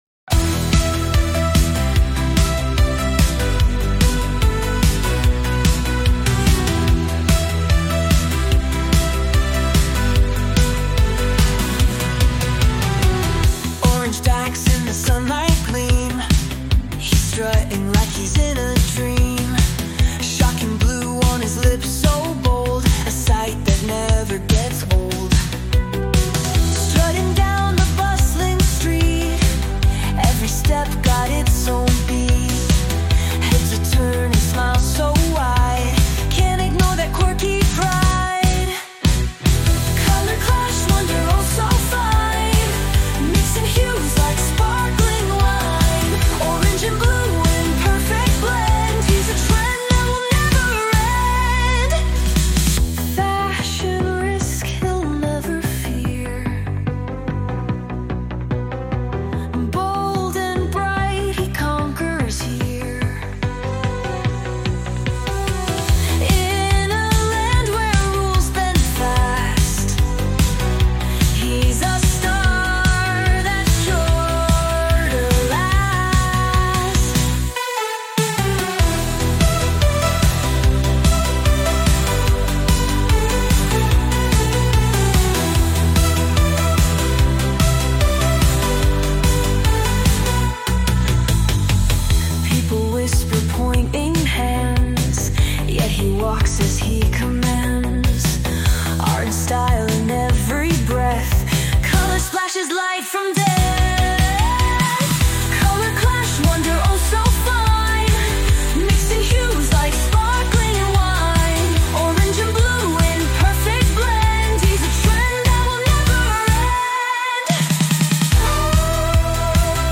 vibrant synth-pop